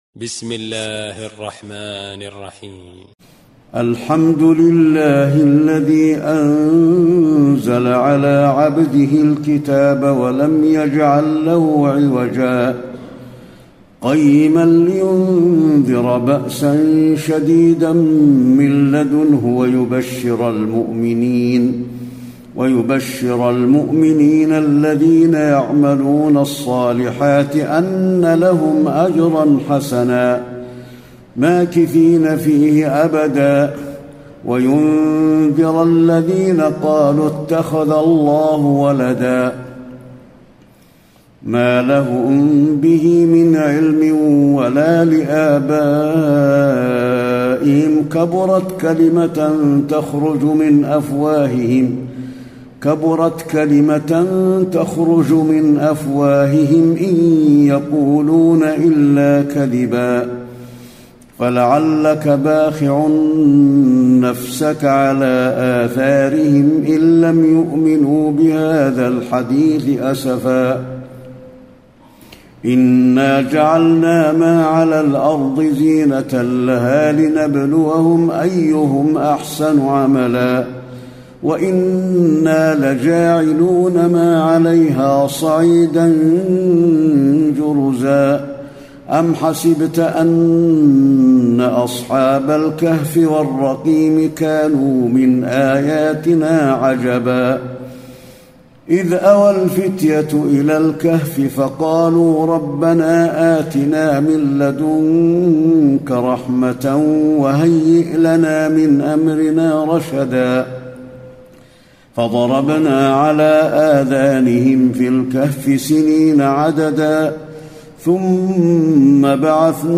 تراويح الليلة الخامسة عشر رمضان 1434هـ سورة الكهف Taraweeh 15 st night Ramadan 1434H from Surah Al-Kahf > تراويح الحرم النبوي عام 1434 🕌 > التراويح - تلاوات الحرمين